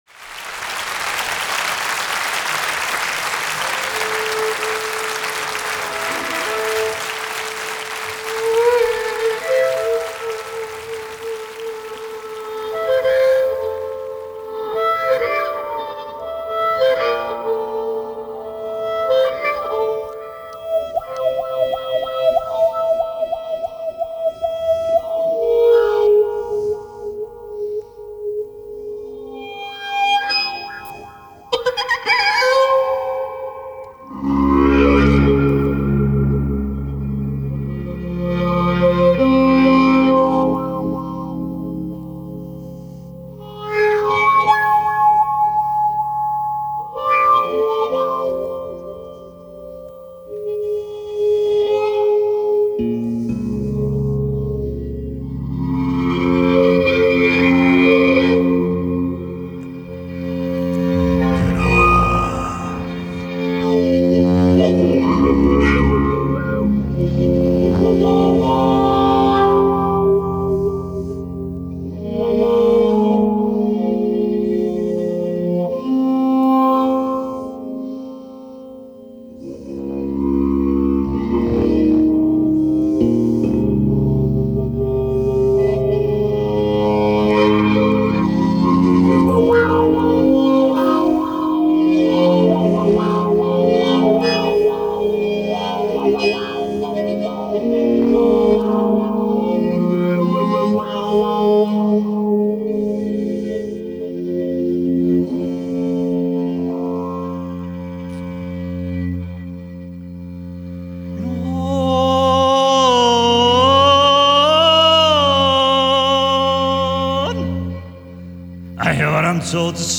Genre: World Music